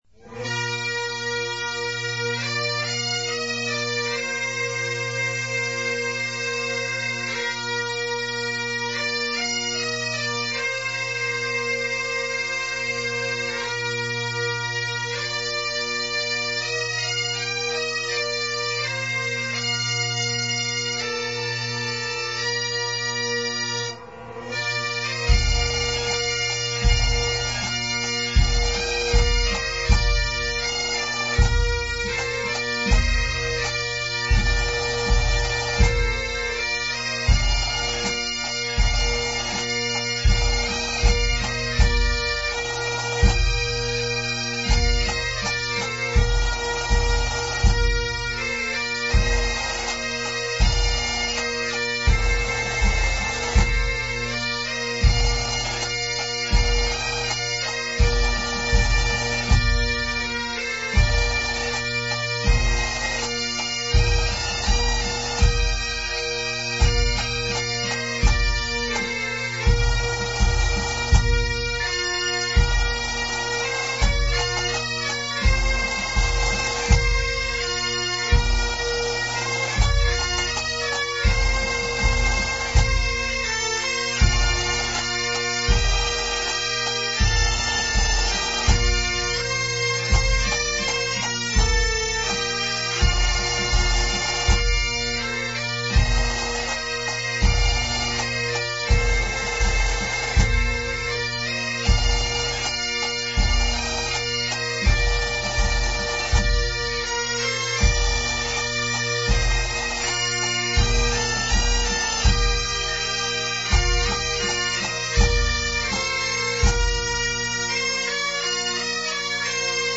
Musicalmente, é unha composición en compás de 4/4 e que emprega a escala habitual da gaita, co Si bemol na armadura.
gaitas_galego.mp3